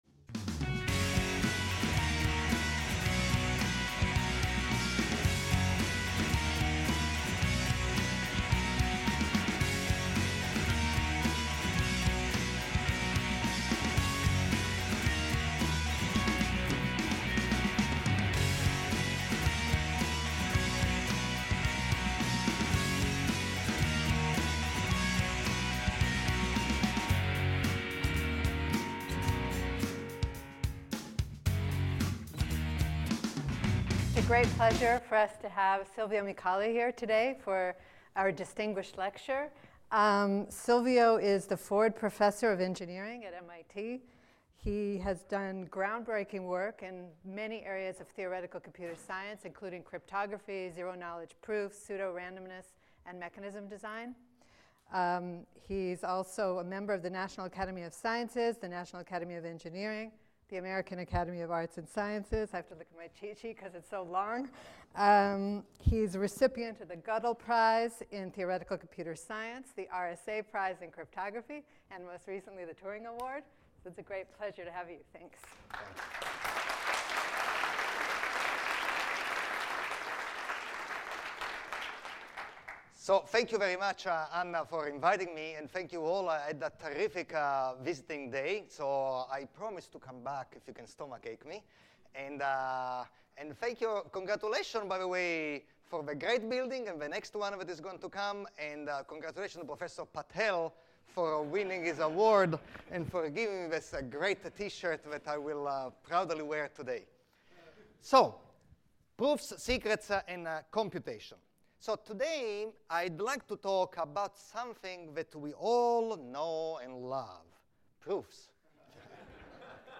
CSE Distinguished Lecture Series